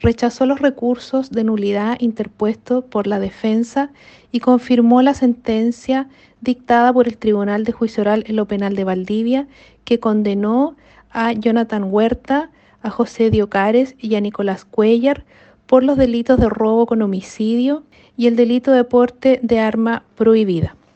La defensa de los condenados pretendía anular el veredicto condenatorio dictado en enero por el Tribunal de Juicio Oral de Valdivia, sin embargo, la solicitud fue rechazada, tal como lo indicó la fiscal de Los Lagos, Claudia Baeza.